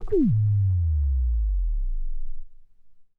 Scratch Steveland 1.wav